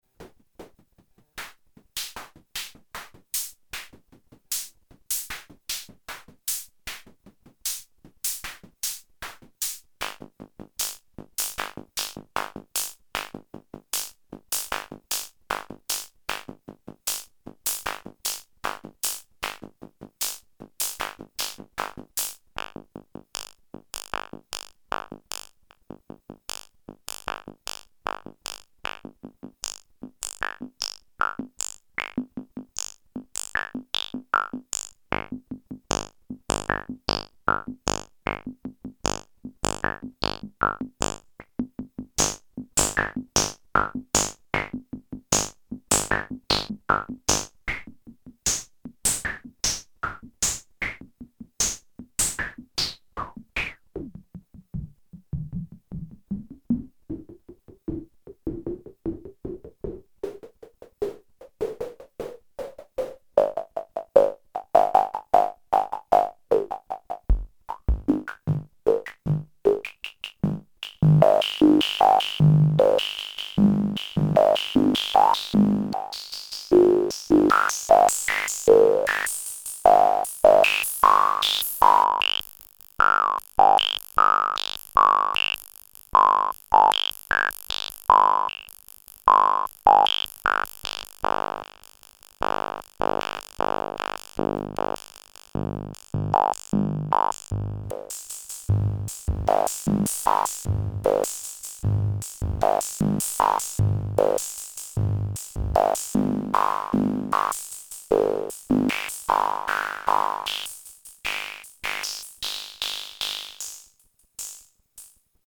2. 2 Sounds combined
squarewave output is applied to 'ext VCF In';
Drum machine